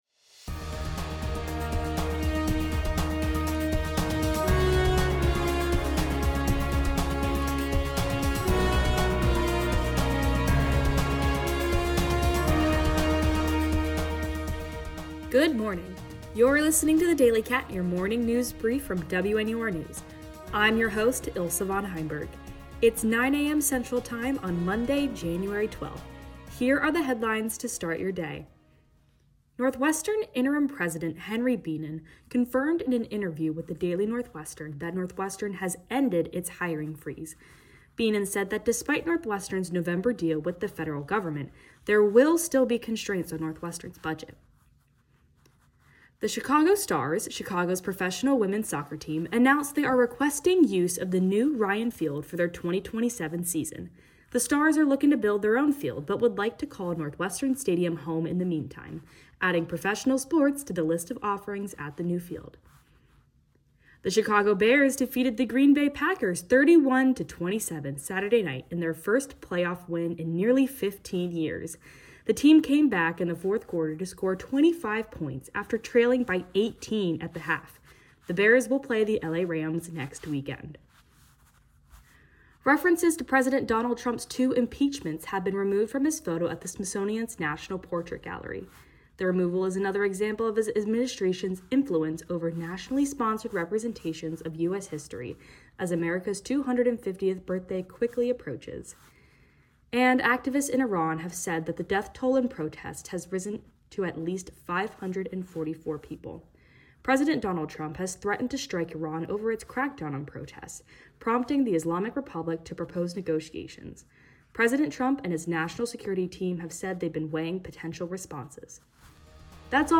January 11, 2026: Chicago Stars, Ryan Field, Northwestern hiring freeze, Henry Bienen, Iran protests, Trump, Trump portrait, Trump Smithsonian, Chicago Bears, Bears Packers. WNUR News broadcasts live at 6 pm CST on Mondays, Wednesdays, and Fridays on WNUR 89.3 FM.